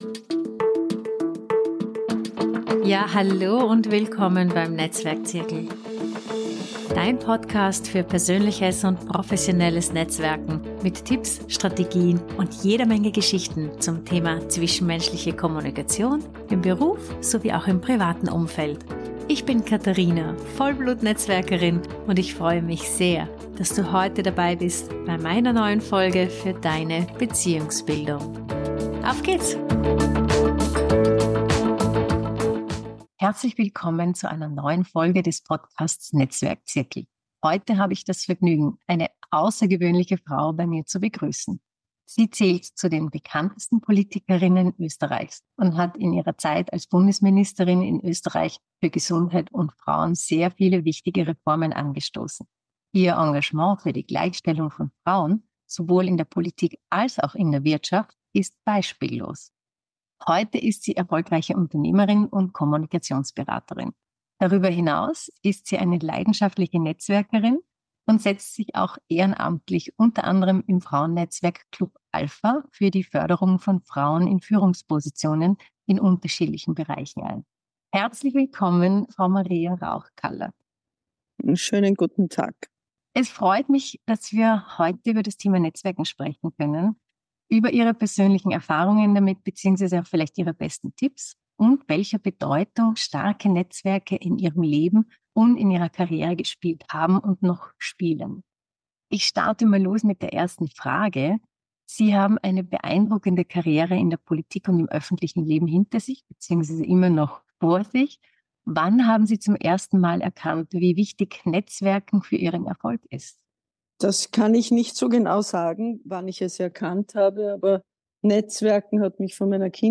Inspirierendes Interview mit der ehemaligen Bundesministerin für Gesundheit und Frauen Maria Rauch-Kallat über die Kunst des Netzwerkens, Frauenförderung und die Bedeutung von starken Verbindungen.